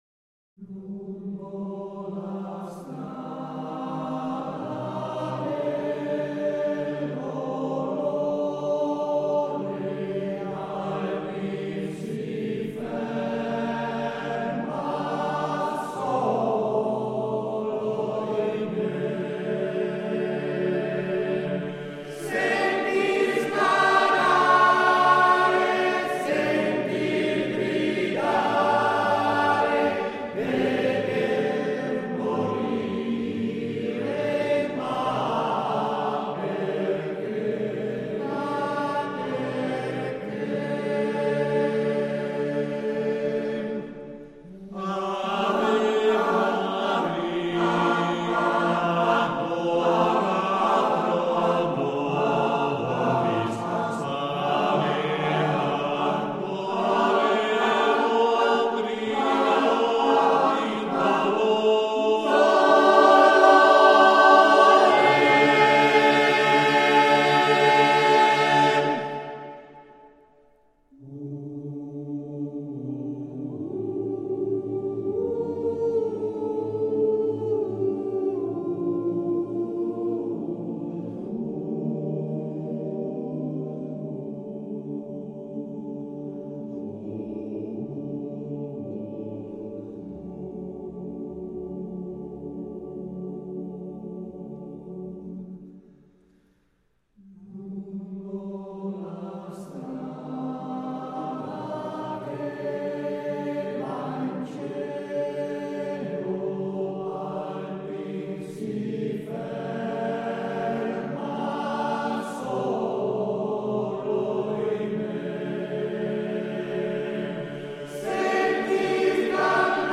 Canto degli Aplini.